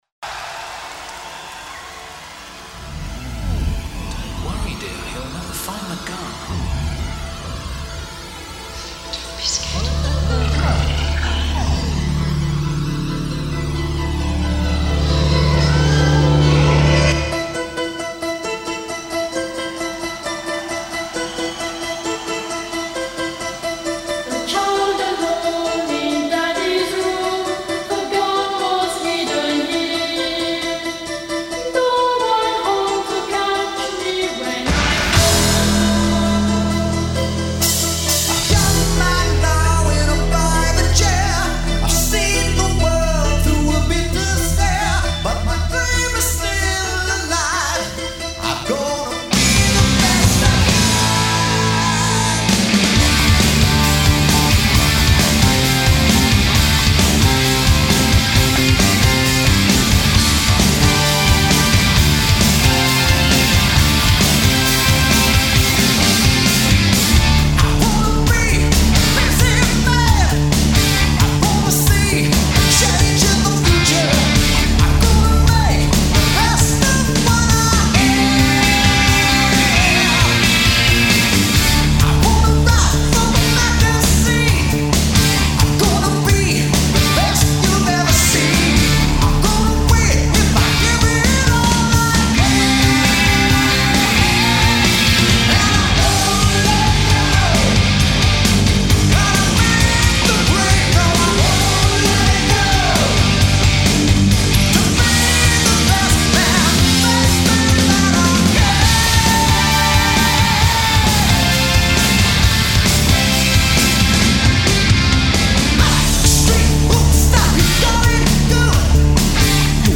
all-American rock